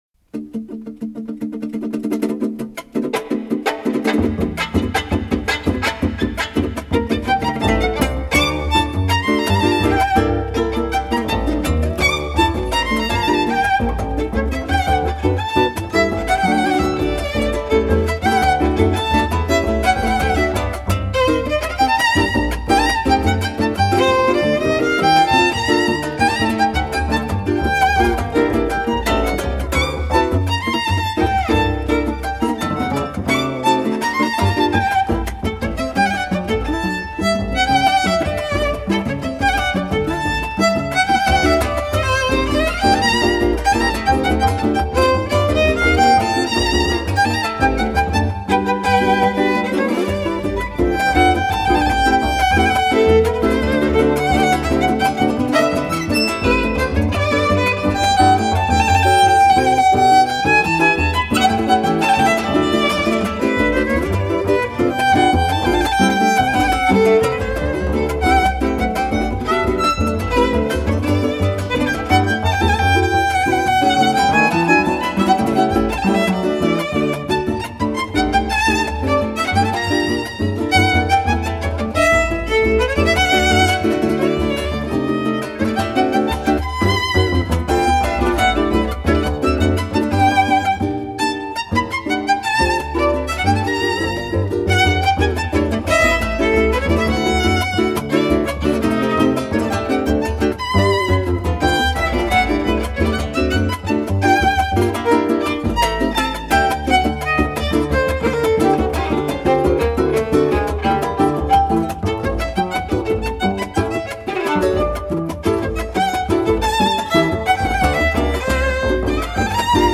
Música latina
La música de América Latina